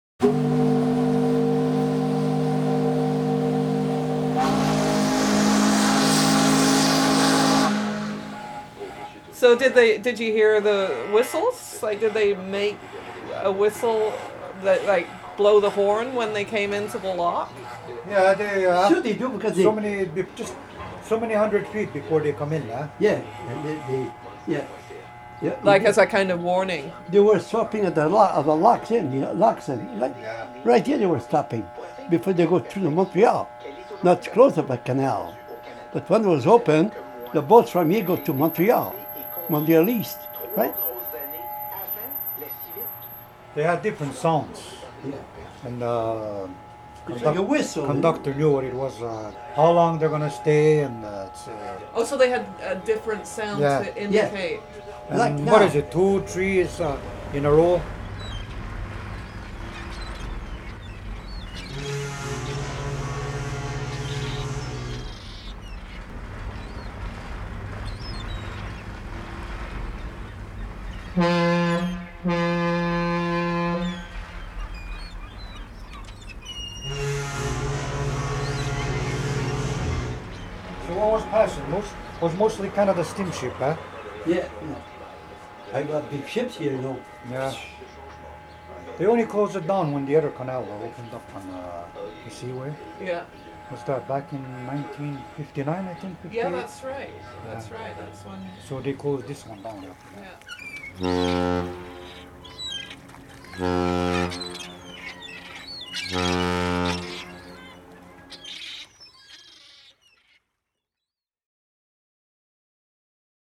Another customer voices his remembrances of the canal and we hear what it may have sounded like 50 years ago.